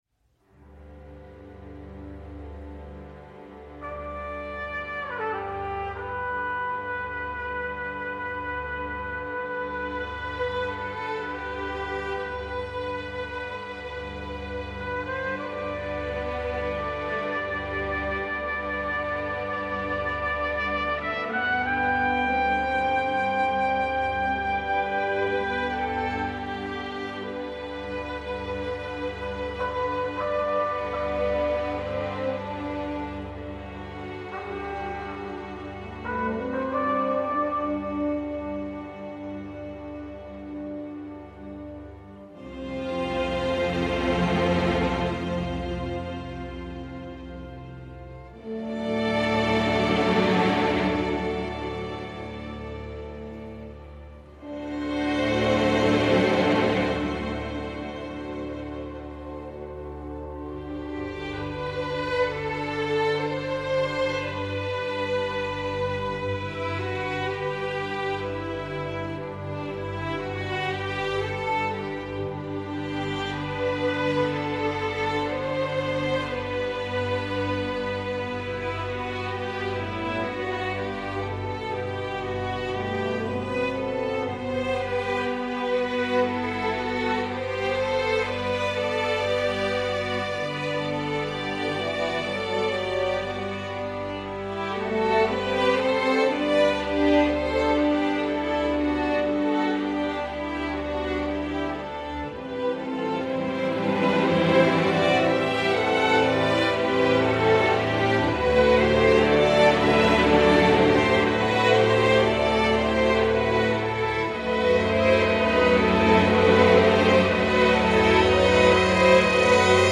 Score très inconfortable en écoute isolée.